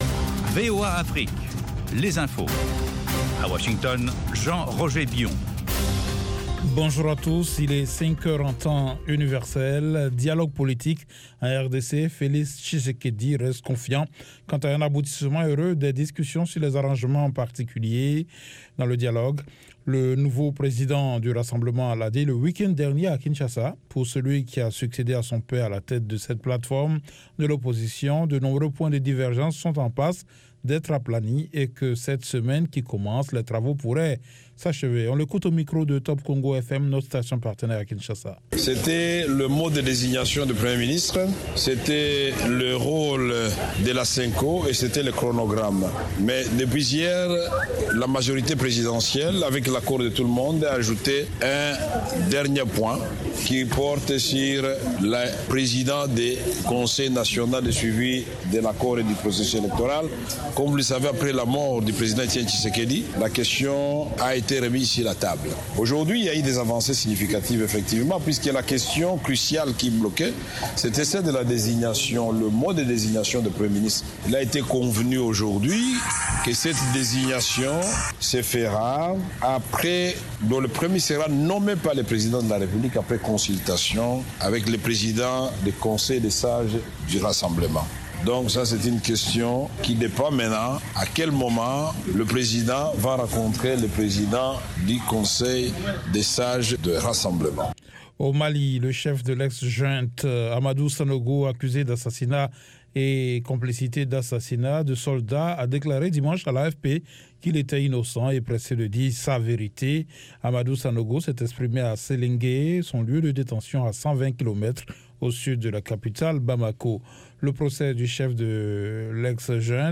Bulletin